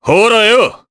Crow-Vox_Attack3_jp.wav